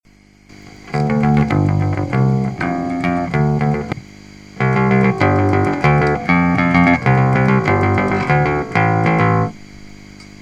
skúsil som tú vodu najväčšiu špinu to zmylo ale vo vinutí furt vidím bordel. neveril som absolútne žiadnym teóriám o návrate zvuku, ale struny majú zase na chvíľu zvuk jak za nova, som zvedavý jak im to dlho vydrží.
Fender Precision Bass USA, Epiphone Thunderbird VINTAGE PRO